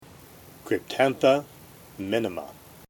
Pronunciation/Pronunciación:
Cryp-tán-tha mí-ni-ma